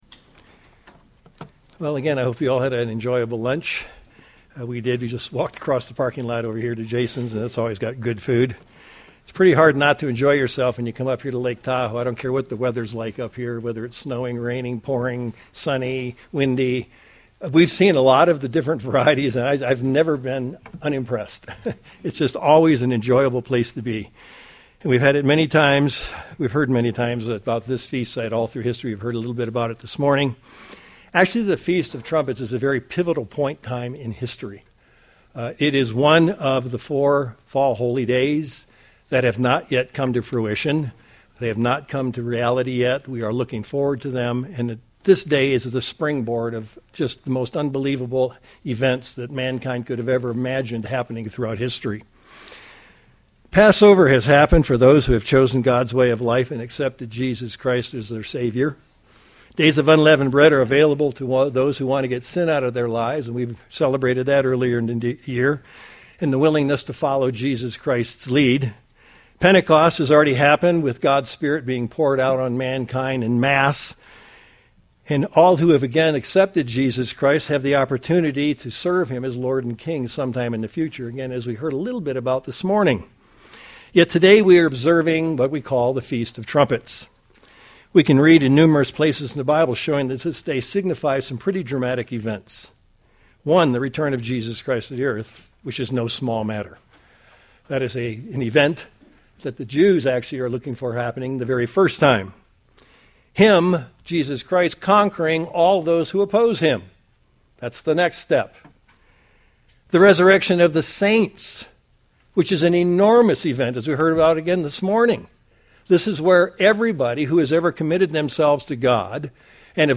Holy Day Services Feast of Trumpets Studying the bible?